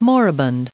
added pronounciation and merriam webster audio
1698_moribund.ogg